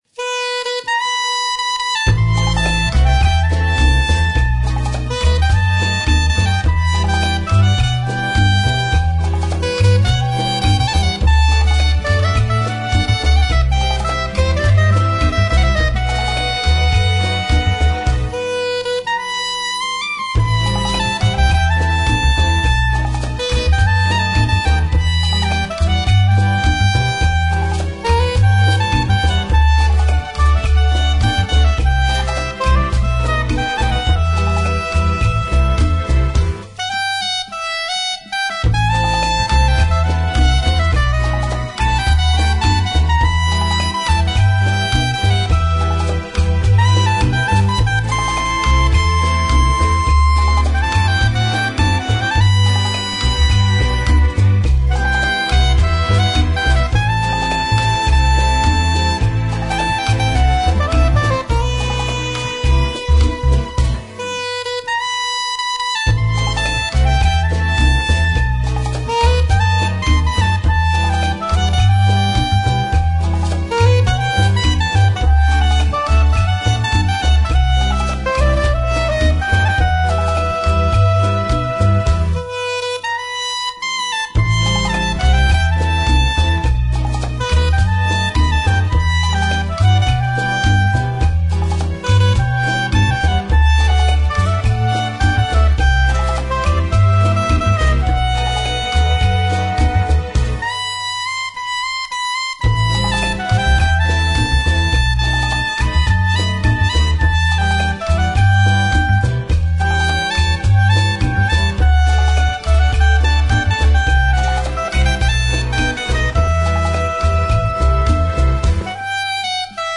Saxofone